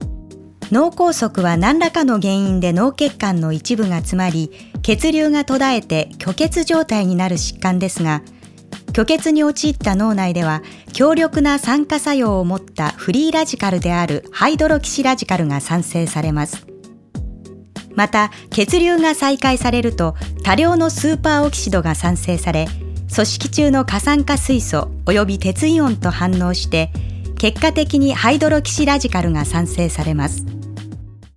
アナウンサー
ボイスサンプル